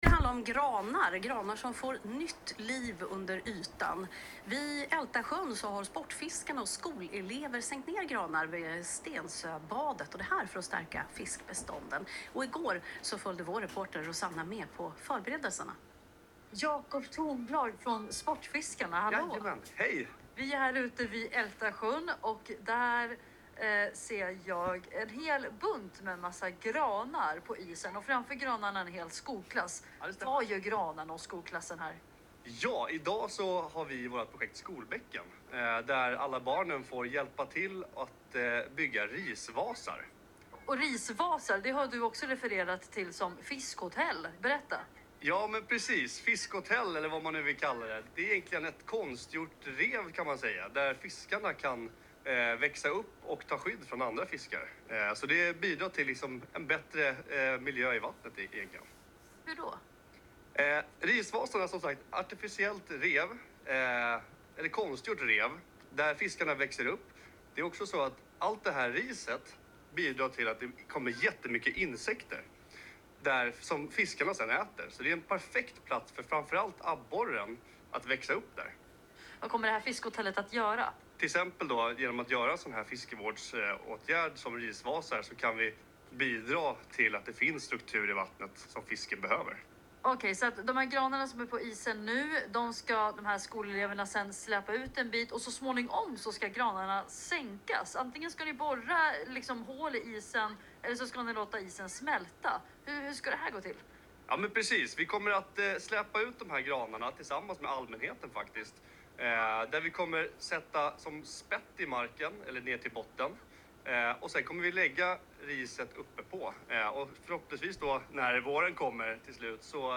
inslag om när Sportfiskarna anlägger risvase på Ältasjön tillsammans med skolungdomar.